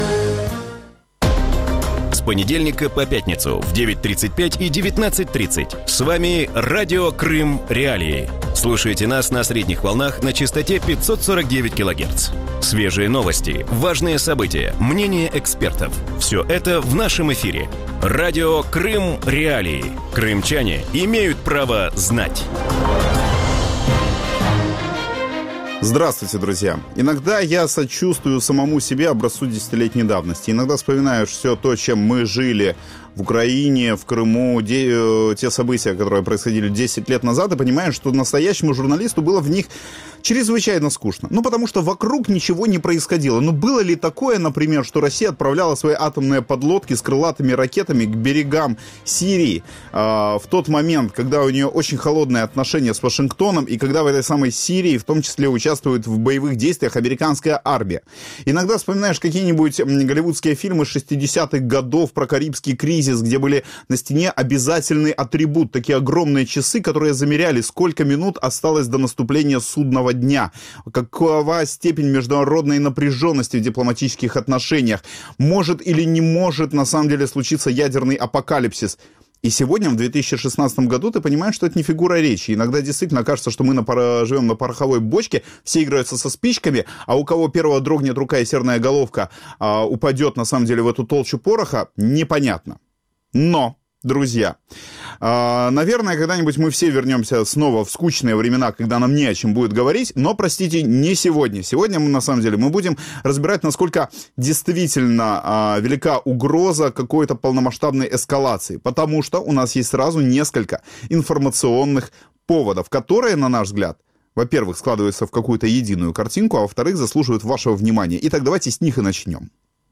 В вечернем эфире Радио Крым.Реалии обсуждают усиление российской военной группировки в Сирии, наращивание военного бюджета и дестабилизацию ситуации в других странах. Готовится ли Россия к полномасштабной войне или «играет мускулами» для устрашения противников?